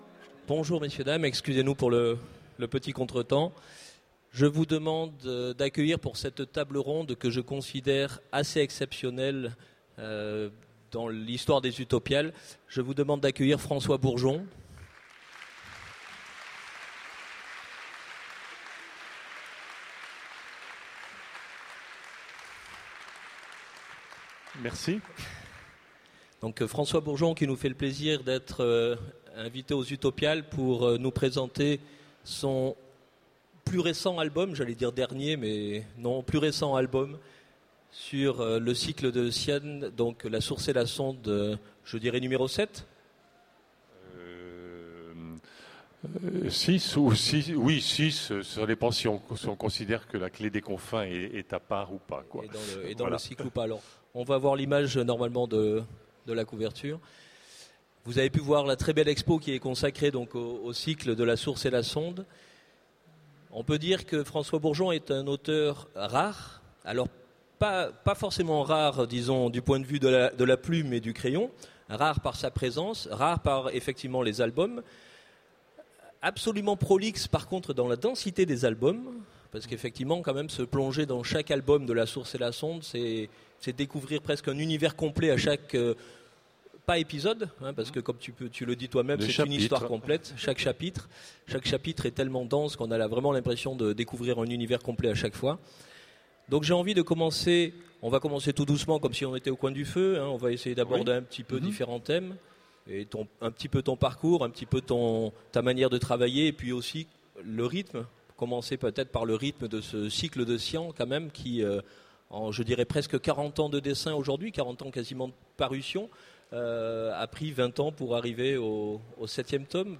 - le 31/10/2017 Partager Commenter Utopiales 2014 : Rencontre avec François Bourgeon Télécharger le MP3 à lire aussi François Bourgeon Genres / Mots-clés Rencontre avec un auteur Conférence Partager cet article